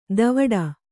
♪ davaḍa